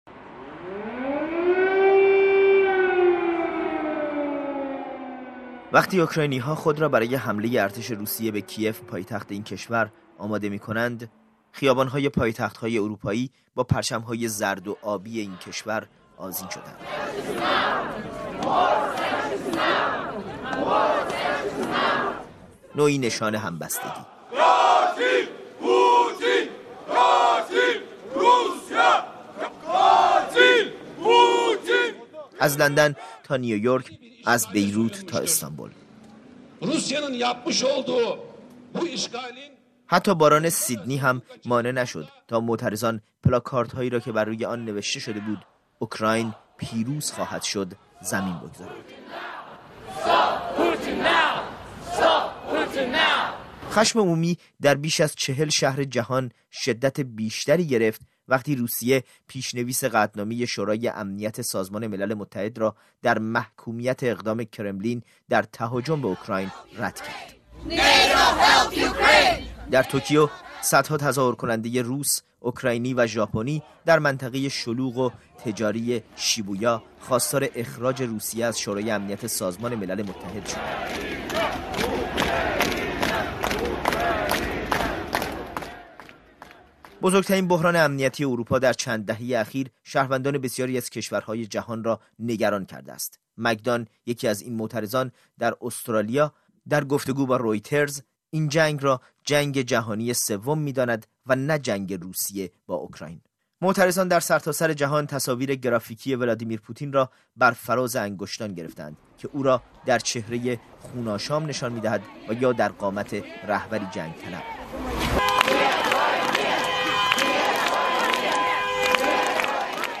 گزارش می‌دهد